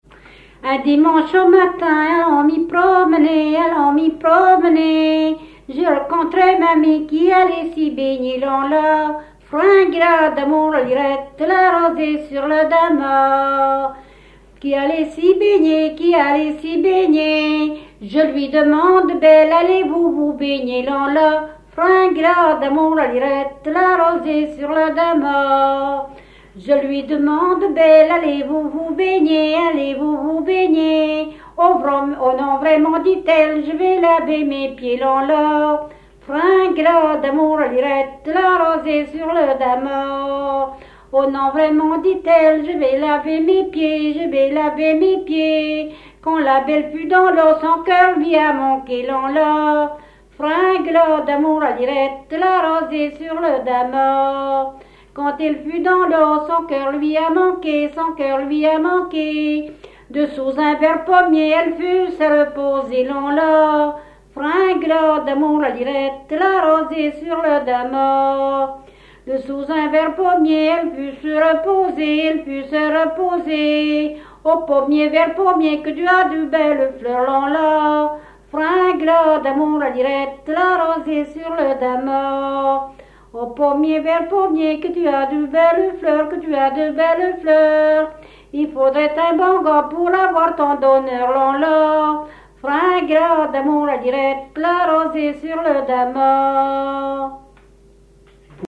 Localisation Barbâtre (Plus d'informations sur Wikipedia)
Genre laisse
Catégorie Pièce musicale inédite